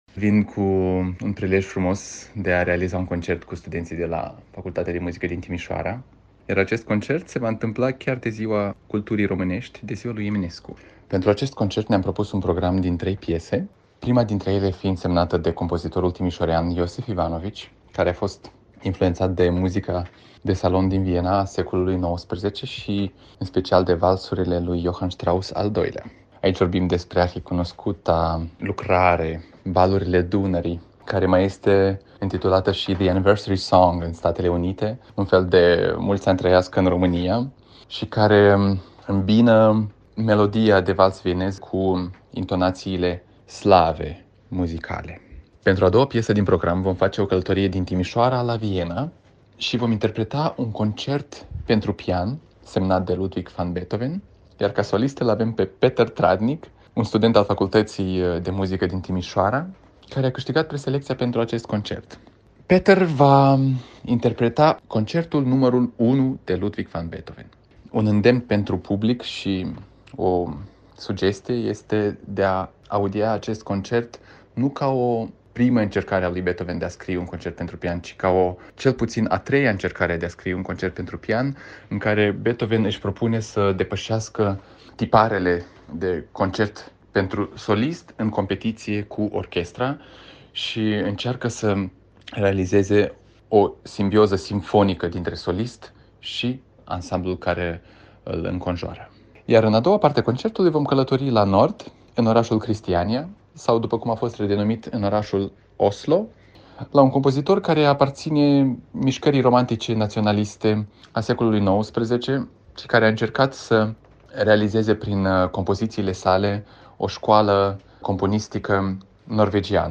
AUDIO/Interviu